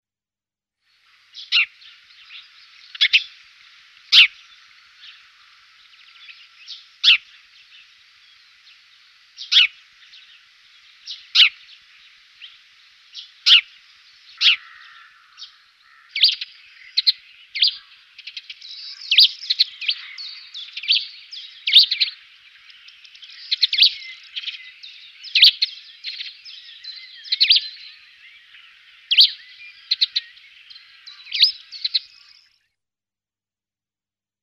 Chant :
Moineau friquet
Le Moineau friquet chuchete, chuchote, pépie. A l'arrivée du printemps, son chant passe souvent inaperçu tant il est peu démonstratif. Ses cris, plus aisément reconnaissables, sont malgré tout très semblables à ceux du domestique, mais plus mélodieux et de tonalité plus élevée.
84TreeSparrow.mp3